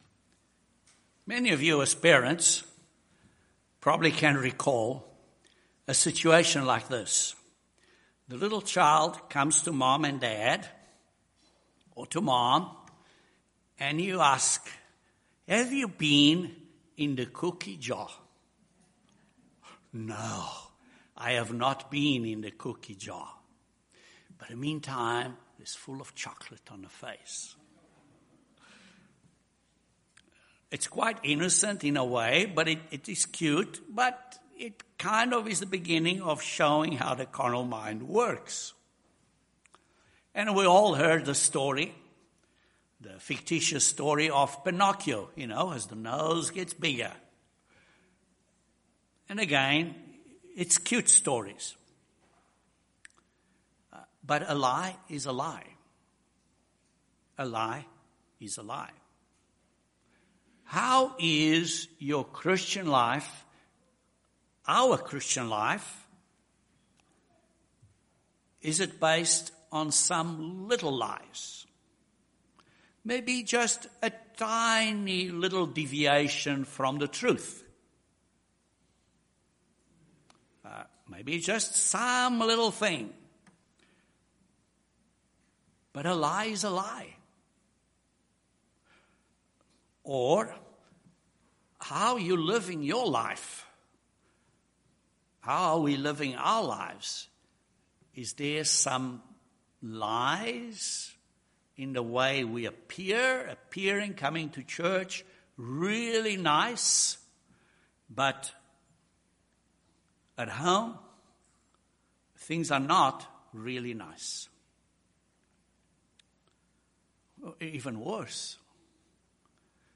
We are instructed to keep the Feast of Unleavened Bread with the unleavened bread of sincerity and truth. This sermon delves a little deeper into these two principles from a spiritual perspective.